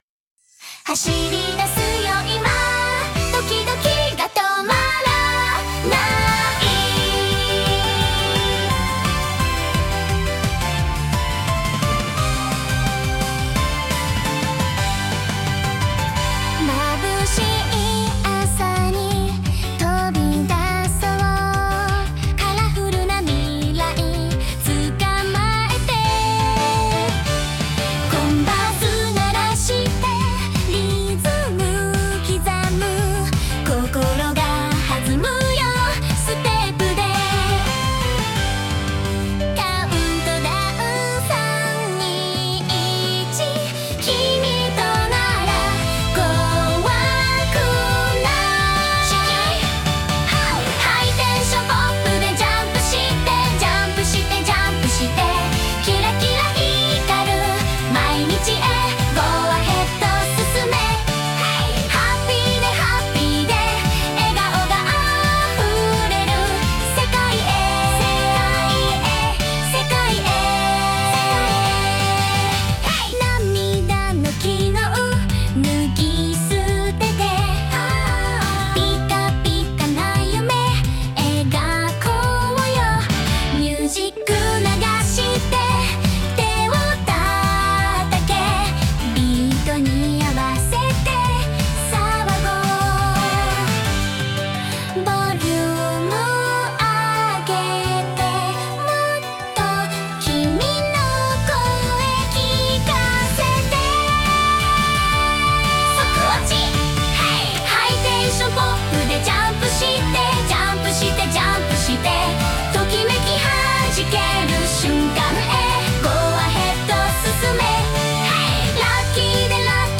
イメージ：J-POP,女性ボーカル,かわいい,キュート,元気,キラキラ,アニメ,アイドル,エネルギッシュ